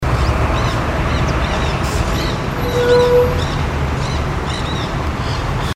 Nanday Parakeet (Aratinga nenday)
Pasaron 8 adultos vocalizando.
Life Stage: Adult
Location or protected area: Reserva Ecológica Costanera Sur (RECS)
Condition: Wild
Certainty: Recorded vocal